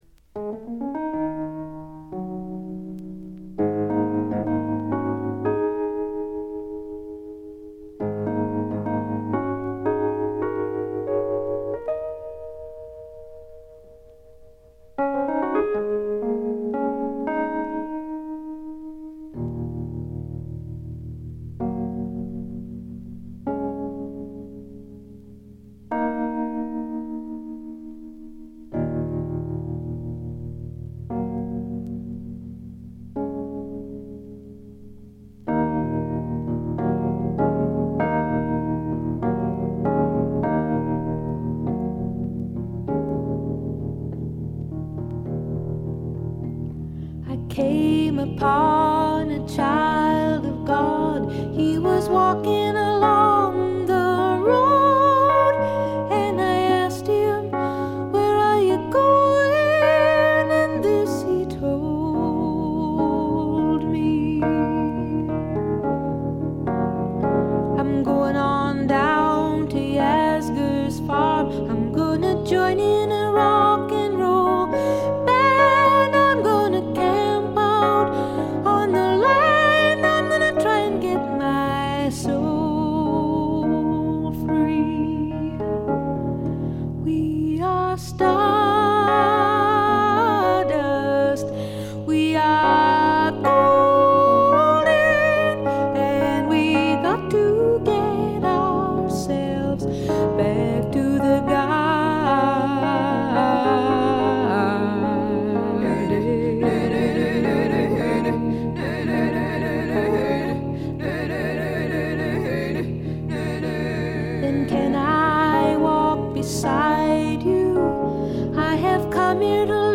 軽微なチリプチ少々。散発的なプツ音が2-3箇所。
美しいことこの上ない女性シンガー・ソングライター名作。
試聴曲は現品からの取り込み音源です。
Recorded At - A&M Studios